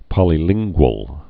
(pŏlē-lĭnggwəl)